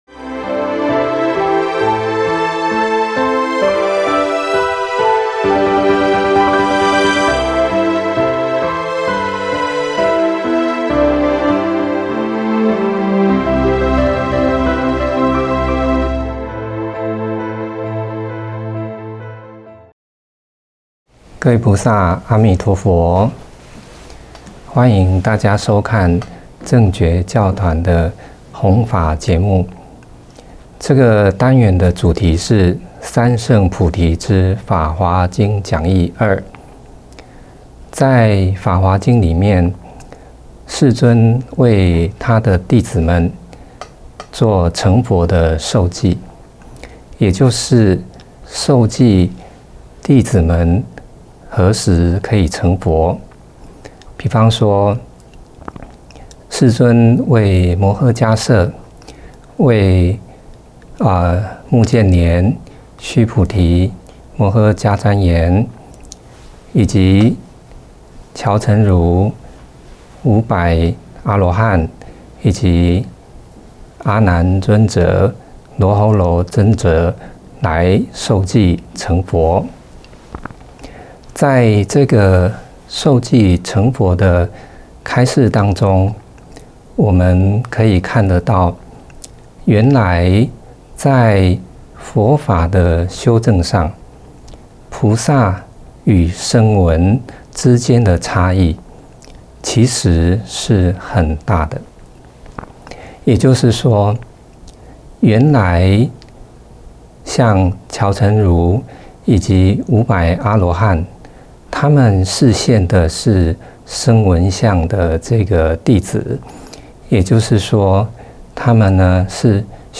三乘菩提系列讲座，正觉同修会影音，同修会音频，同修会视频